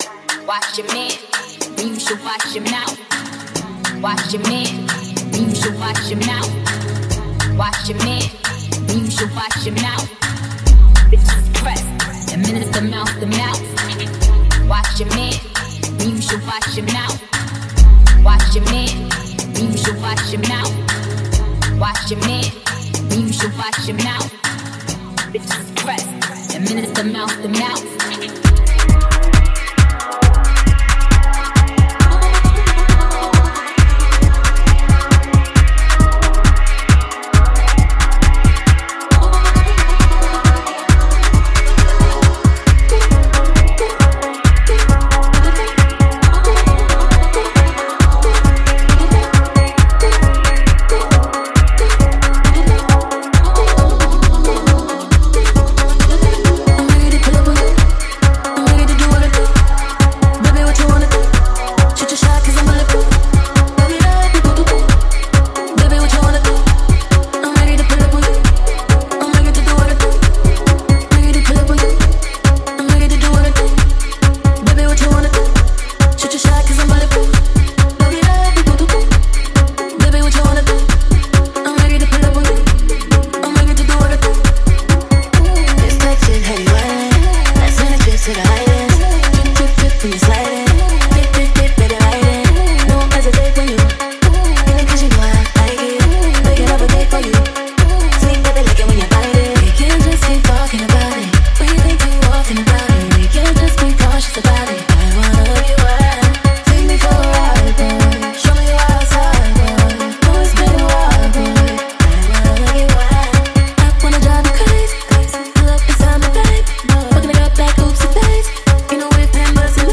Dance Drum & Bass Electronic Techno